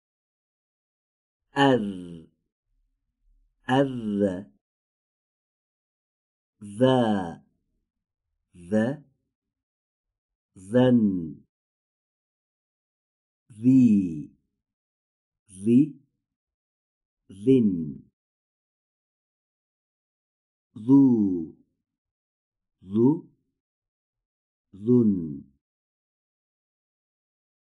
📝تلفظ حرف ذ
این حرف مانند حرف «ث» از برخورد سر زبان به پشت سر دندان‌های پیشین بالا به تلفظ درمی‌آید.
👌هنگام تولید حرف «ذ»، هوای سازنده آن، از محل برخورد سر زبان و سر دندان‌های پشین بالا به طور سایشی خارج می‌گردد و تارهای صوتی را نیز به ارتعاش درمی‌آورد.